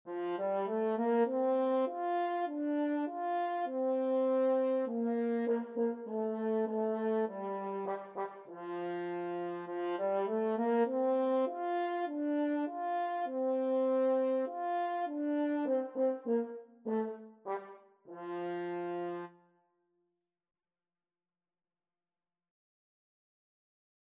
French Horn version
4/4 (View more 4/4 Music)
F4-F5
Moderato